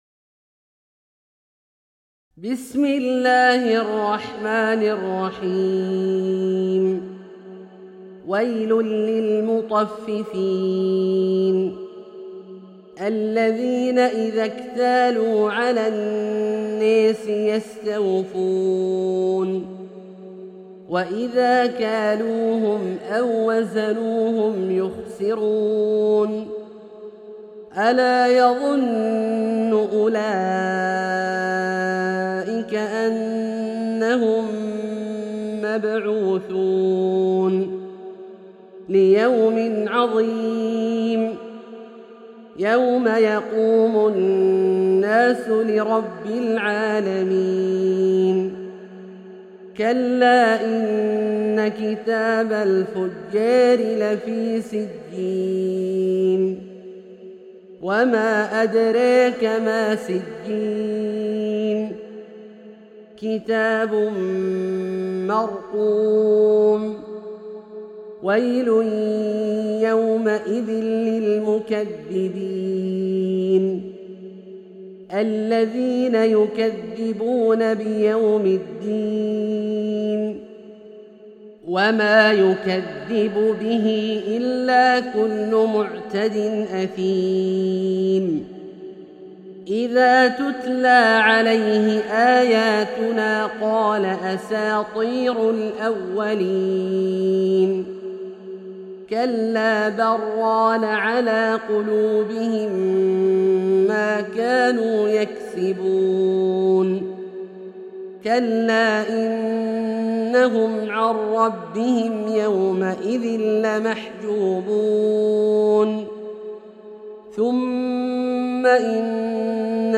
Surat Al-Muttaffifeen > 11 > Mushaf - Abdullah Al-Juhani Recitations